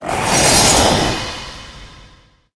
Cri de Zacian dans sa forme Épée Suprême dans Pokémon HOME.
Cri_0888_Épée_Suprême_HOME.ogg